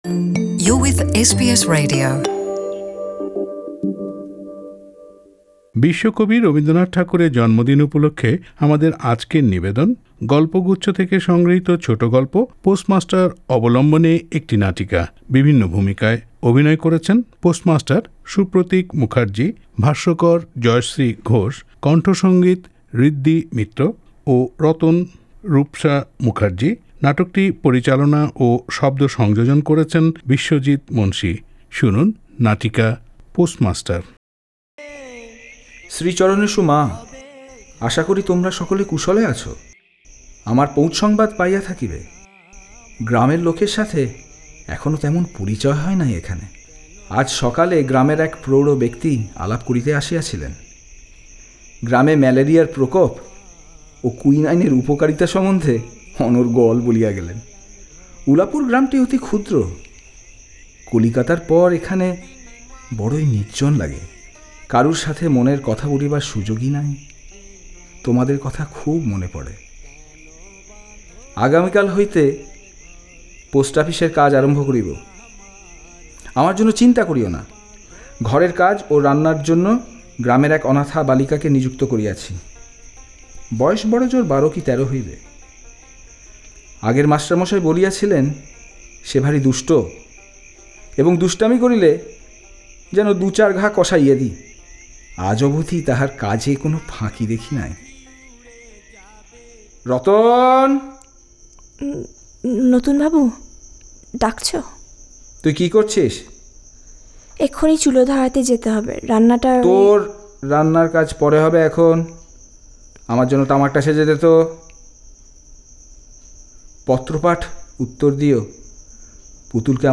In his birth anniversary SBS Bangla broadcast a drama based on his famous short story 'Postmaster'.